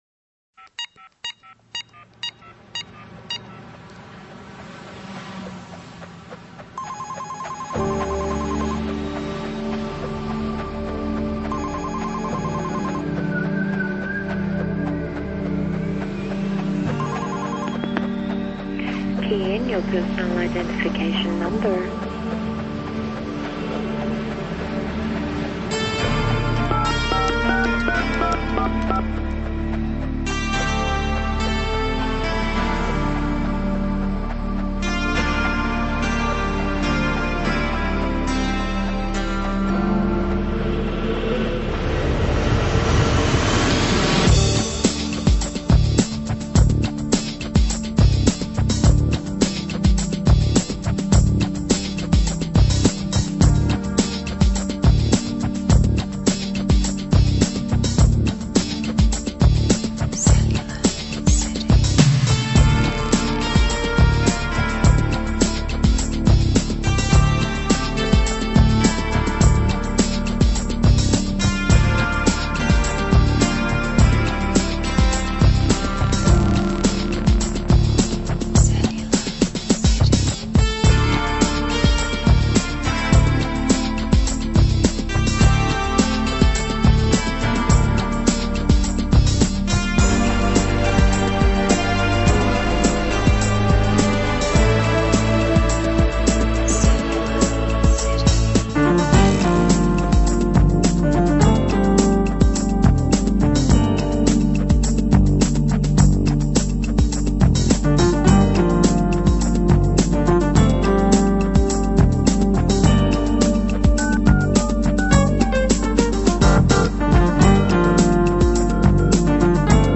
呈現全然的動感都會風格